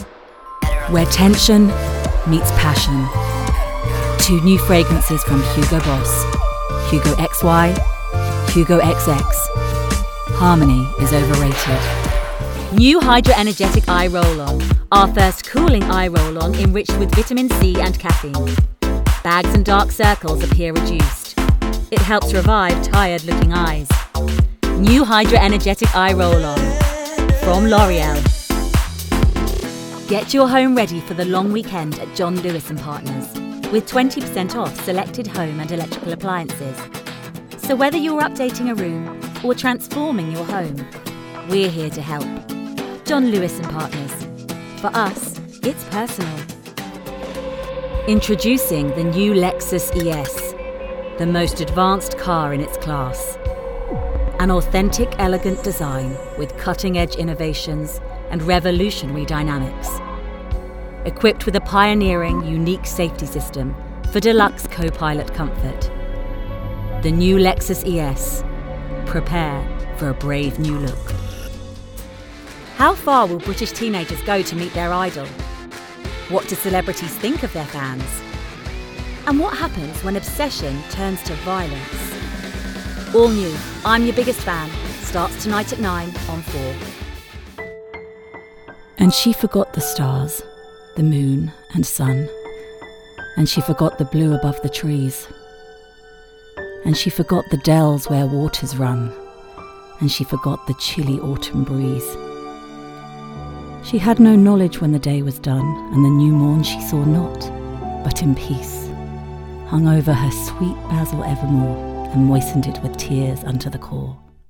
Female
Estuary, London, RP ('Received Pronunciation')
Commercial, Smooth, Friendly